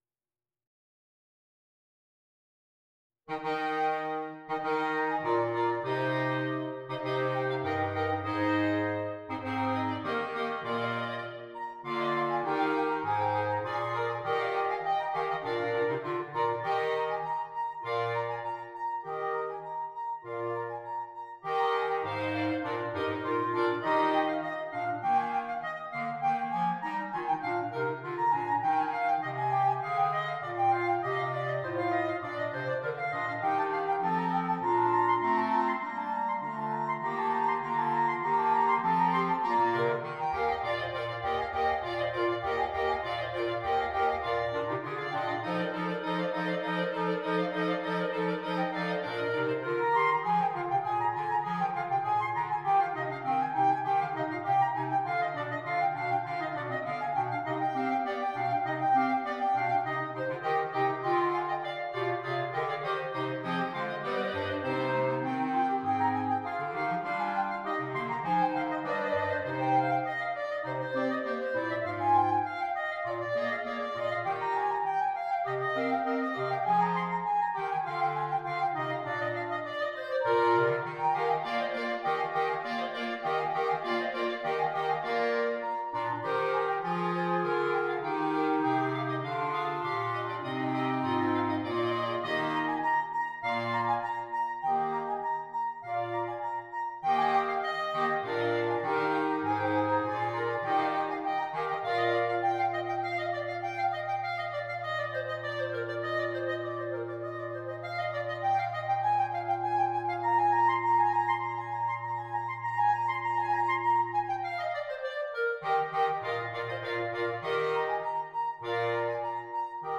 5 Clarinets, Bass Clarinet